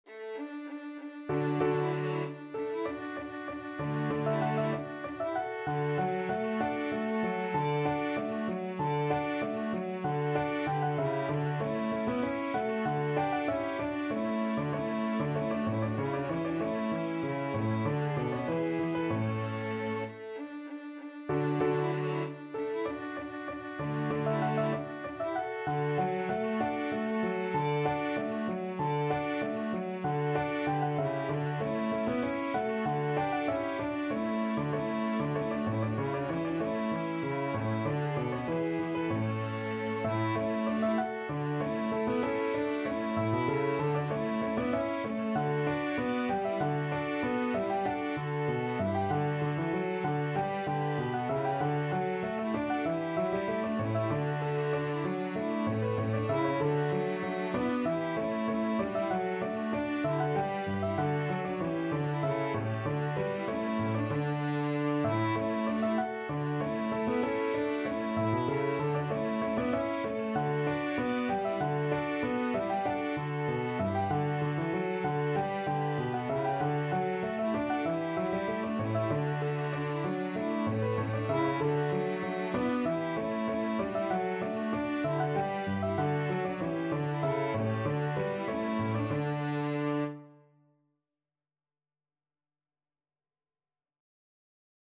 Free Sheet music for Piano Quintet
Violin 1Violin 2ViolaCelloPiano
D major (Sounding Pitch) (View more D major Music for Piano Quintet )
= 96 Allegro (View more music marked Allegro)
4/4 (View more 4/4 Music)
Piano Quintet  (View more Intermediate Piano Quintet Music)
Classical (View more Classical Piano Quintet Music)